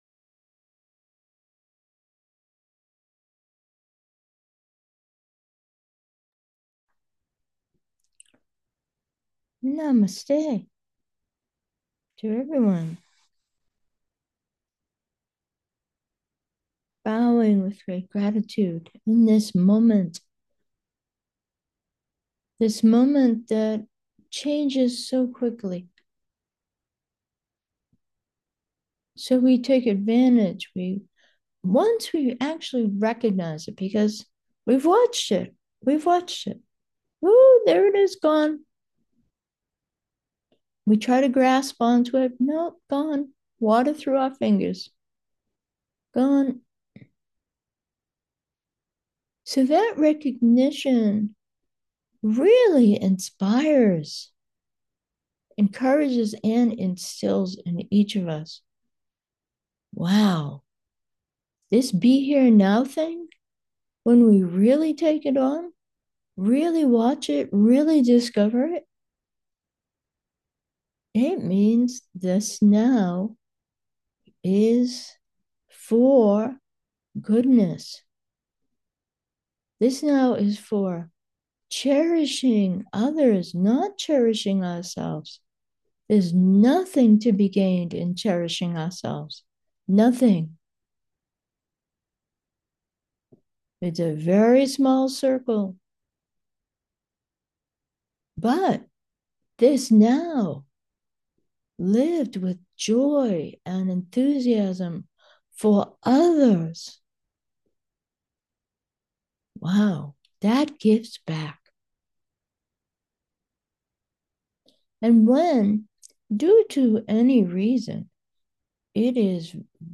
Meditation: this moment namaste